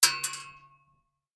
8abddf23c7 Divergent / mods / Bullet Shell Sounds / gamedata / sounds / bullet_shells / pistol_metal_1.ogg 25 KiB (Stored with Git LFS) Raw History
pistol_metal_1.ogg